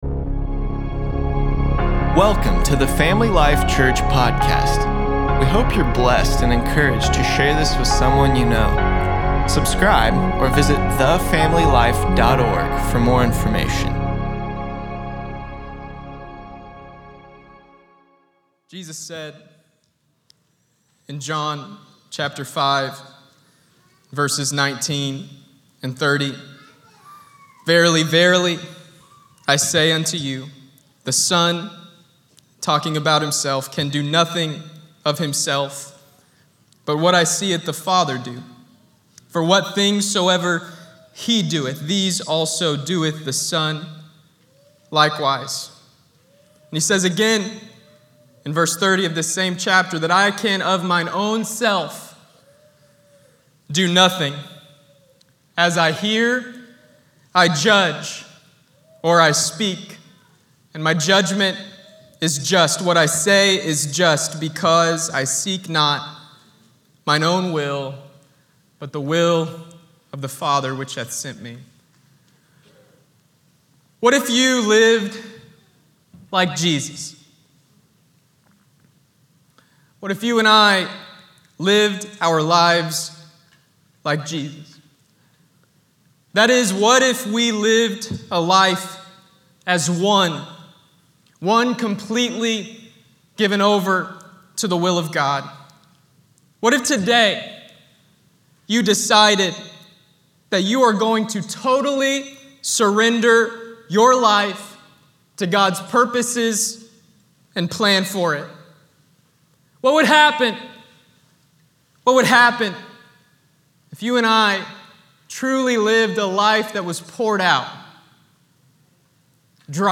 3-28-21_sermon.mp3